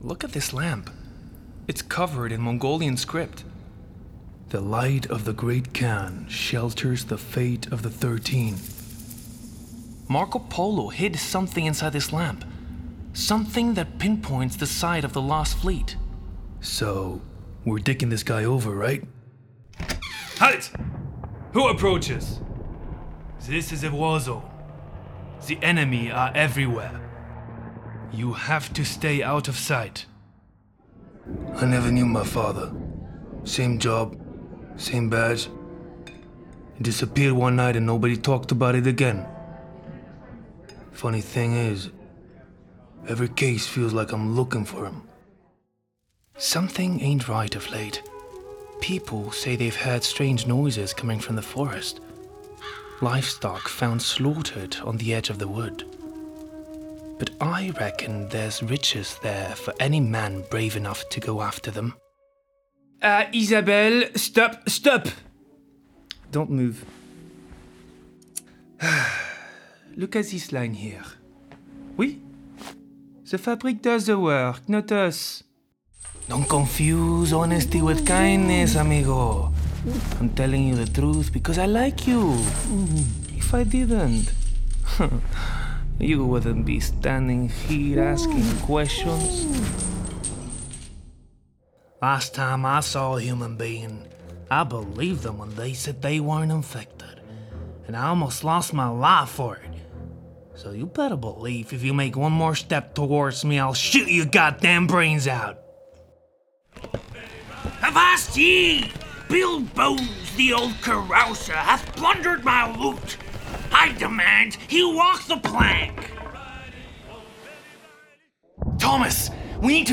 sehr variabel, hell, fein, zart, markant
Mittel minus (25-45)
Charakter Reel Englisch
Game, Audio Drama (Hörspiel), Comedy, Imitation
American, French, Spanish (Latin America)